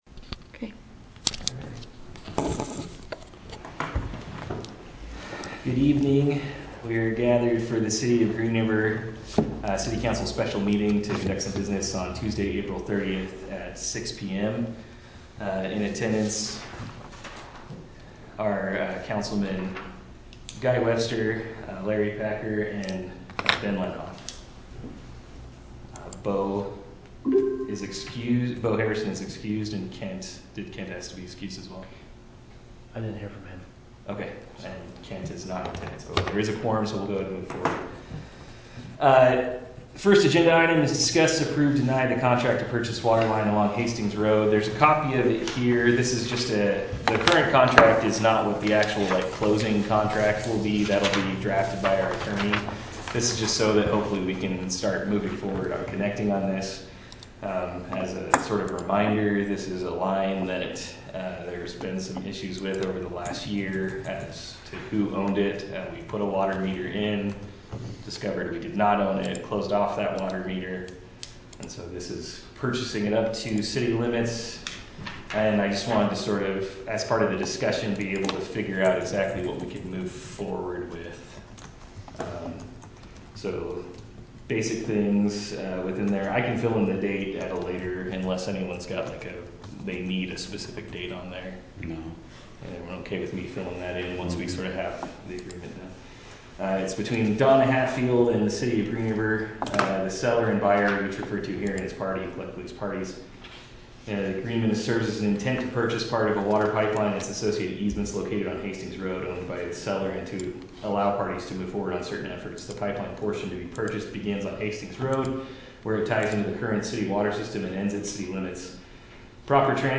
Green River City Council Special Meeting